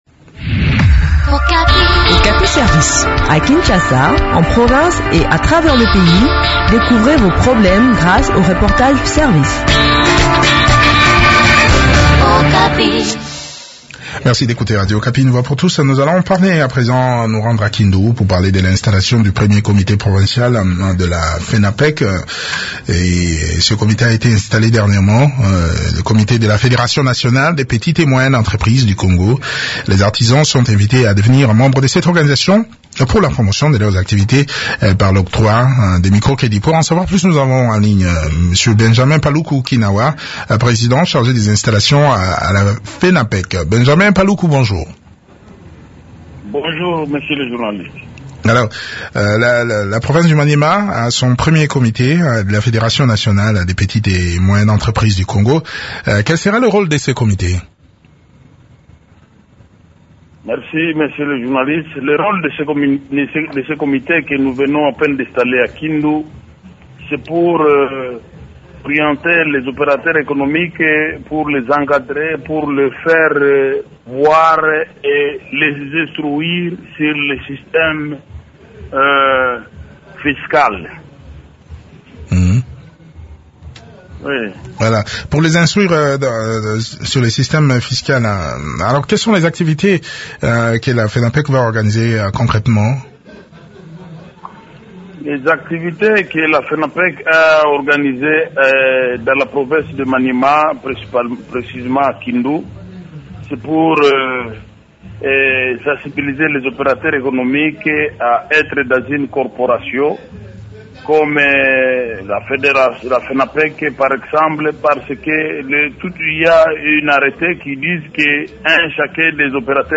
parle de l’importance de l’implantation de cette structure au micro